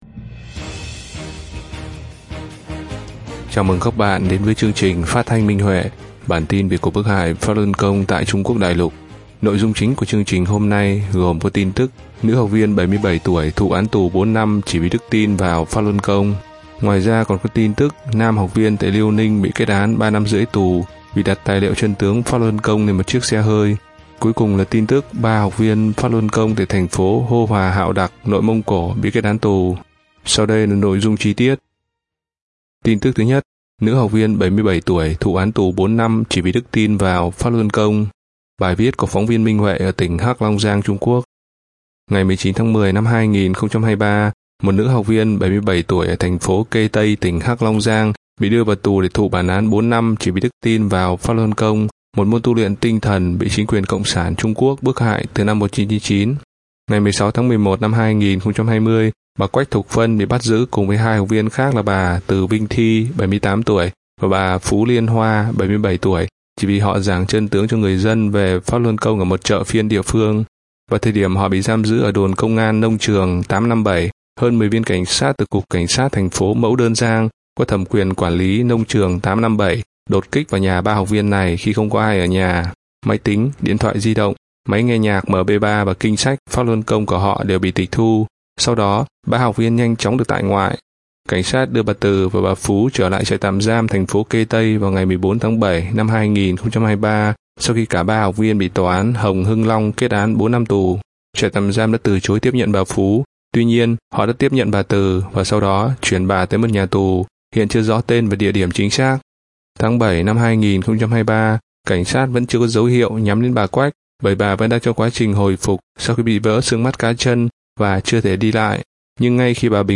Chương trình phát thanh số 55: Tin tức Pháp Luân Đại Pháp tại Đại Lục – Ngày 30/11/2023